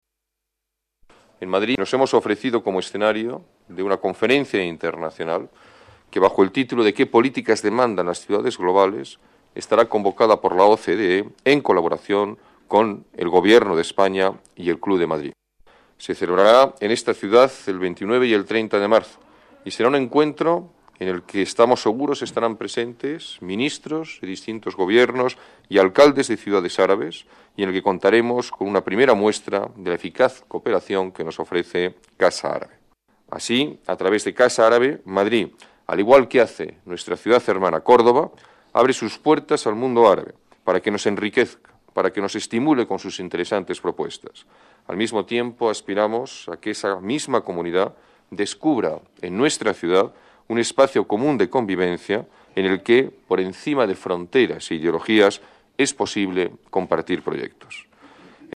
Nueva ventana:Declaraciones alcalde, Alberto Ruiz-Gallardón: Casa Árabe, Madrid eje vertebrador